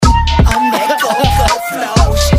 Mystery chirp sounds
The instrument is a flute.
But once exported, it's there and it's very loud. 2-3x louder than the actual instrument. A single super loud chirp. Like what you'd hear when your fire alarms battery is running low.